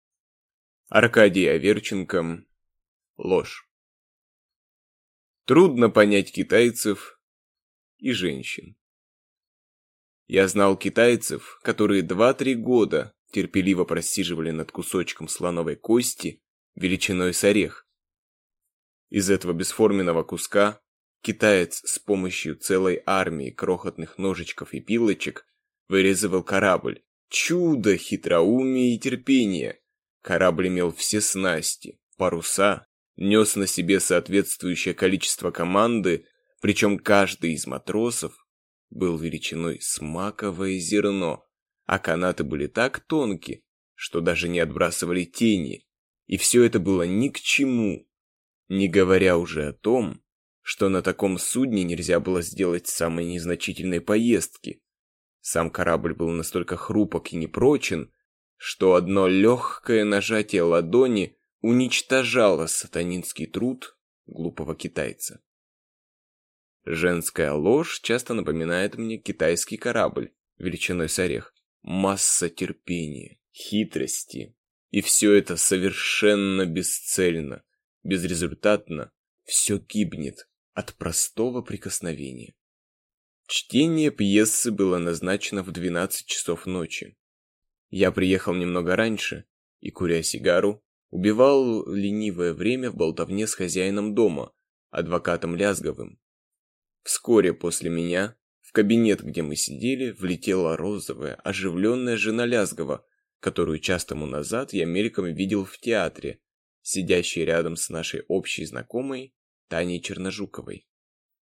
Aудиокнига Ложь